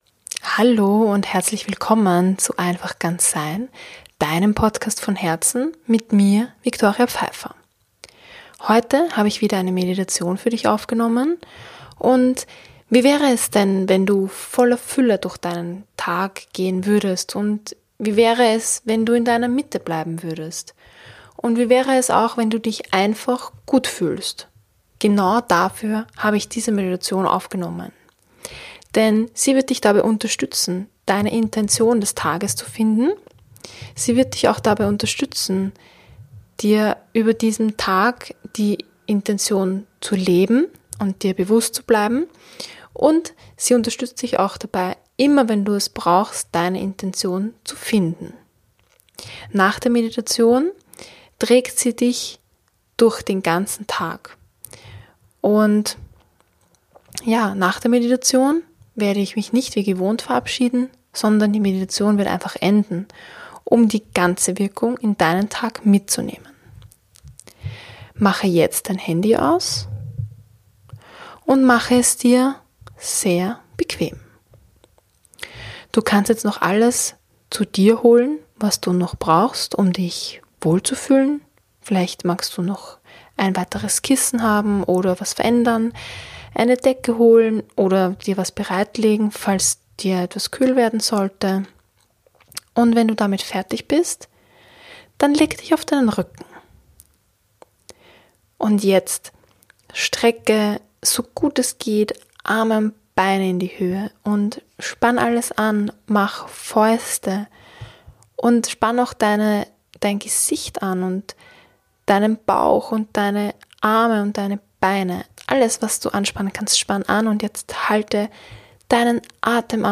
So ist auch diese Meditation entstanden.